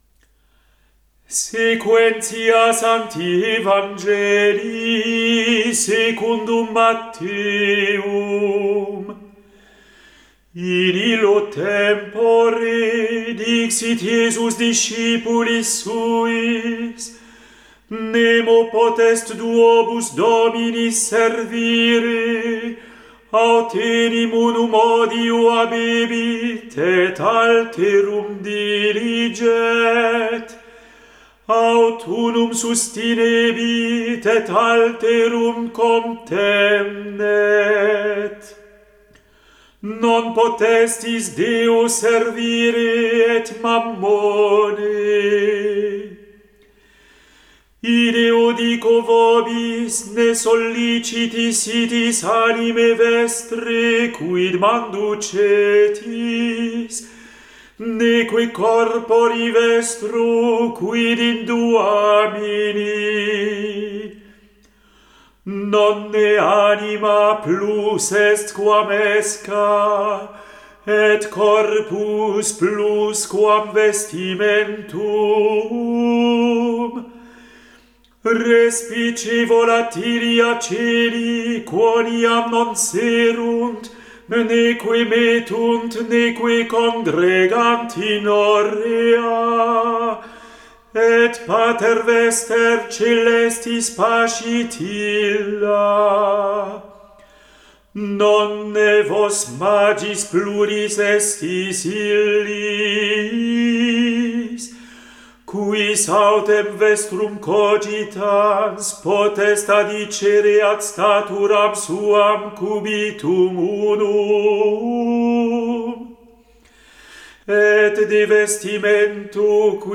Evangelium